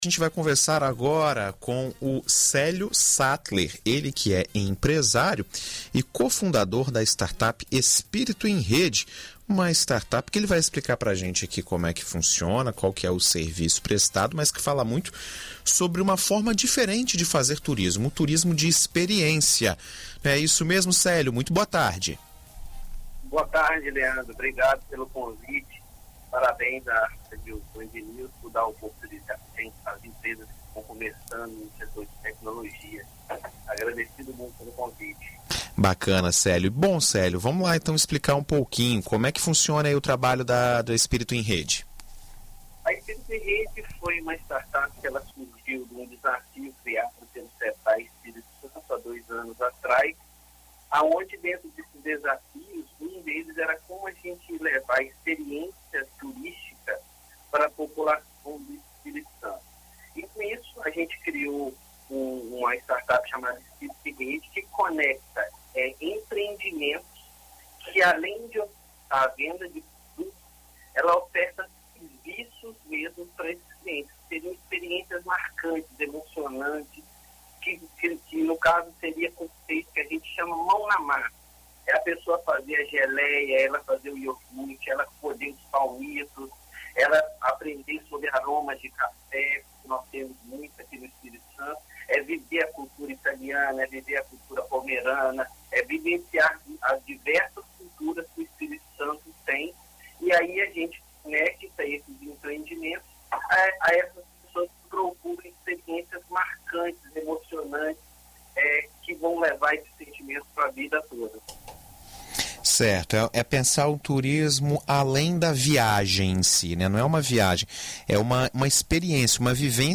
Entrevista: Conheça o turismo de experiência